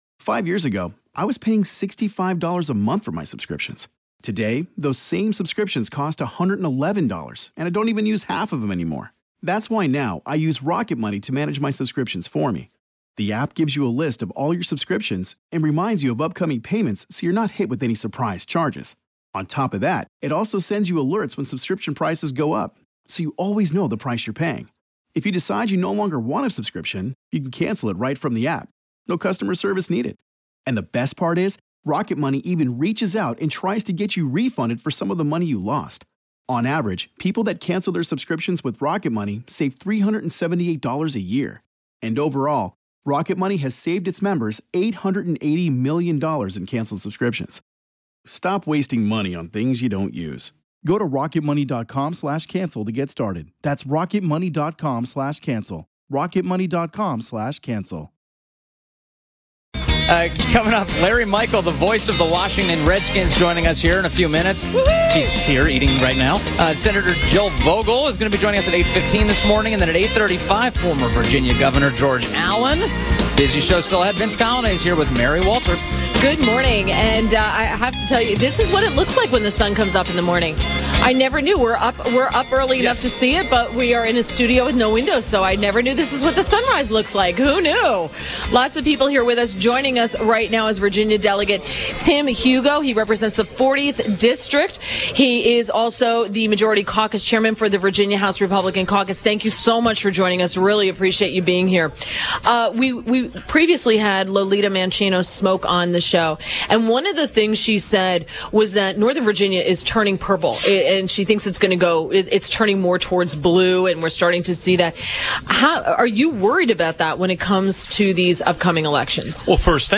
WMAL Interview - VA DEL. TIM HUGO -11.03.17